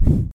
poleWoosh.ogg